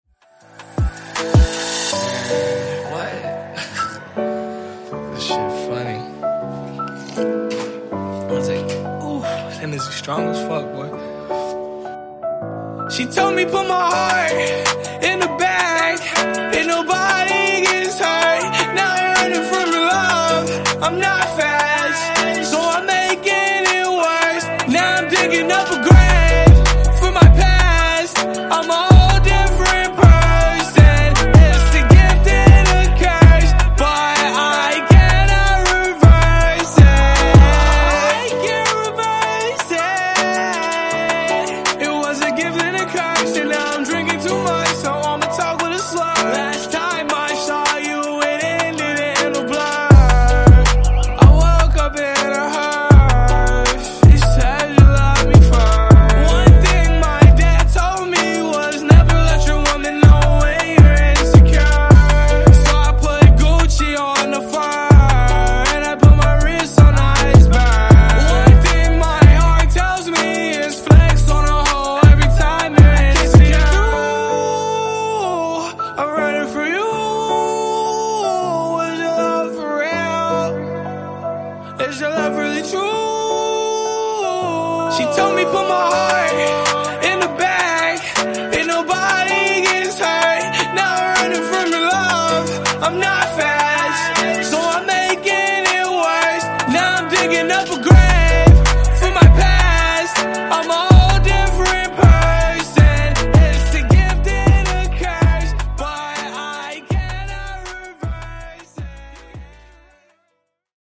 Genre: RE-DRUM Version: Clean BPM: 104 Time